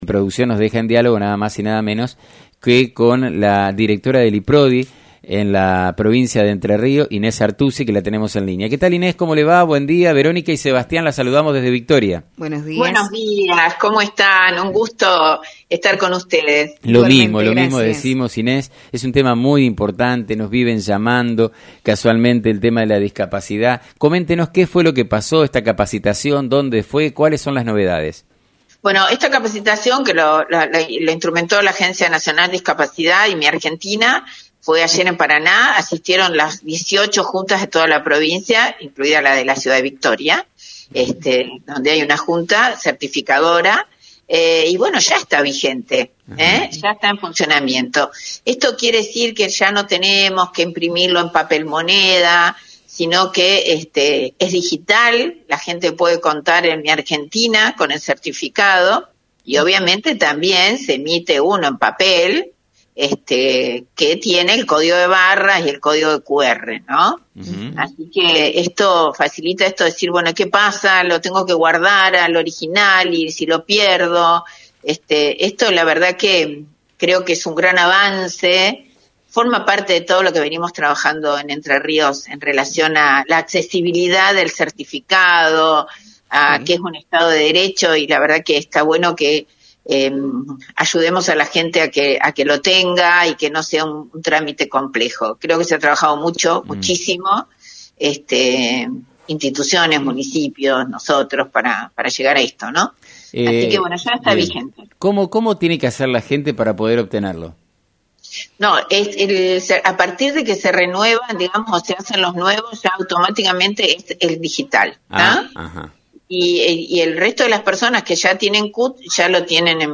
La directora del Instituto Provincial de Discapacidad (Iprodi), Inés Artusi en “Burro de Arranque” por FM90.3